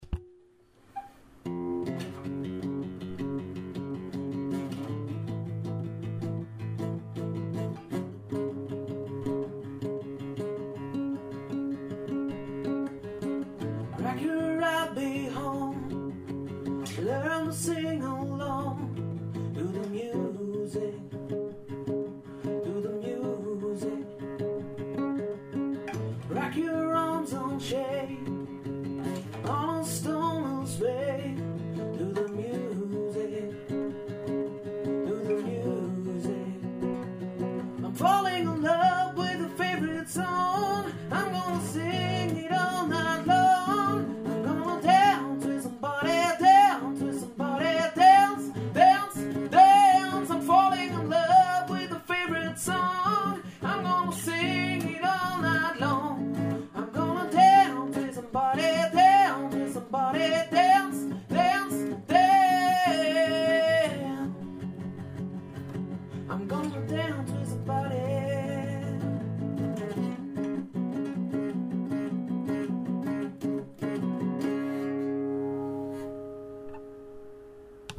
1 Stimme, eine Gitarre
schöner Sound im kleinen Rahmen
• Unplugged